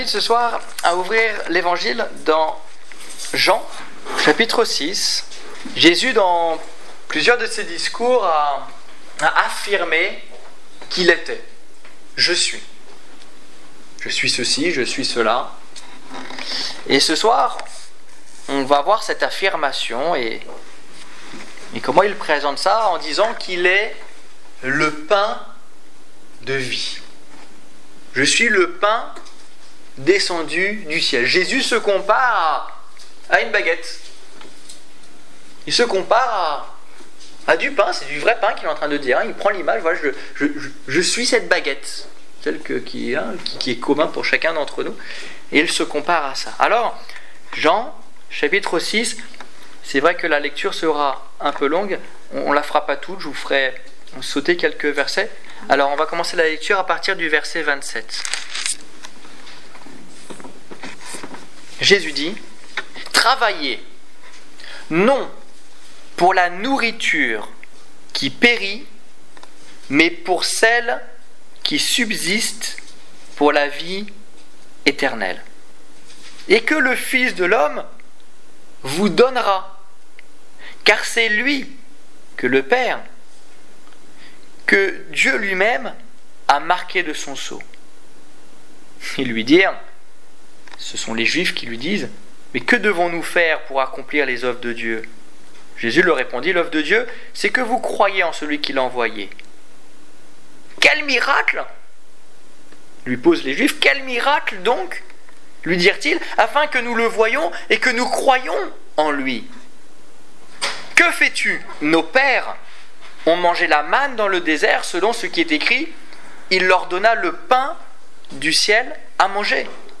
Je suis le pain de vie Détails Prédications - liste complète Évangélisation du 22 mai 2015 Ecoutez l'enregistrement de ce message à l'aide du lecteur